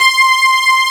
14 STRG C5-L.wav